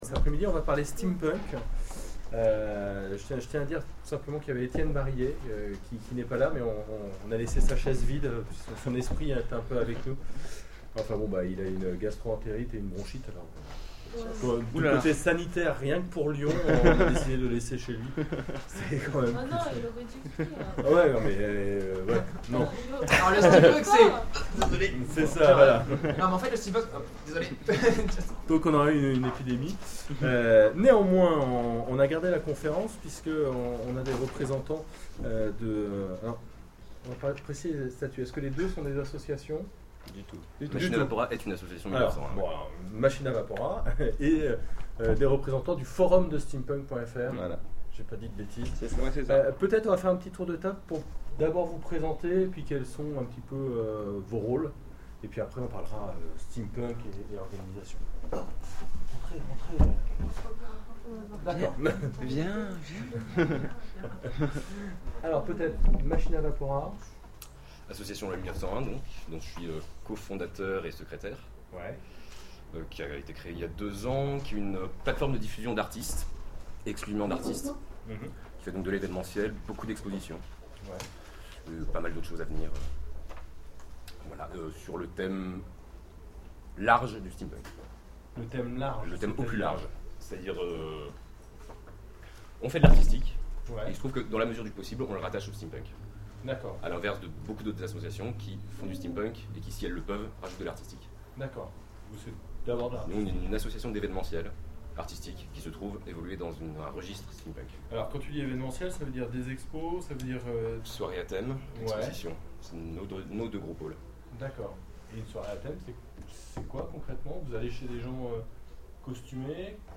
Octogones 2012 : conférence Steampunk